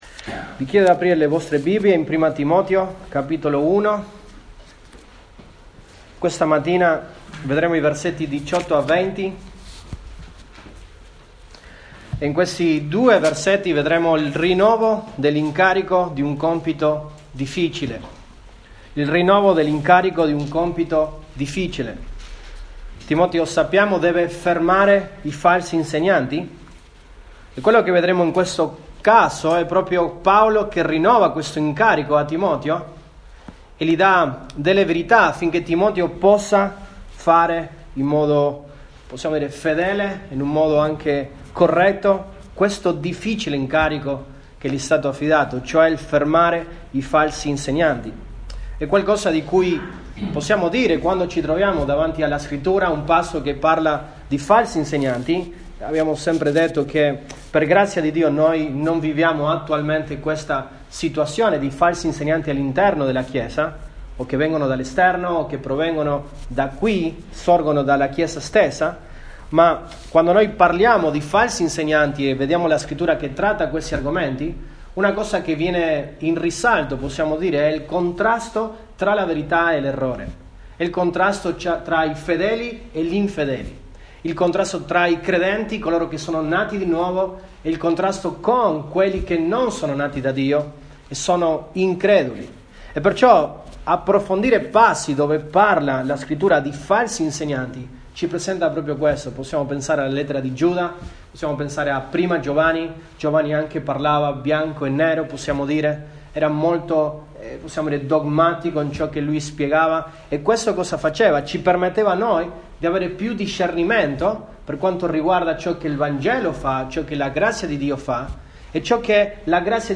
Nov 29, 2020 L’affidamento di un compito difficile, fermare i falsi insegnanti MP3 Note Sermoni in questa serie L’affidamento di un compito difficile, fermare i falsi insegnanti.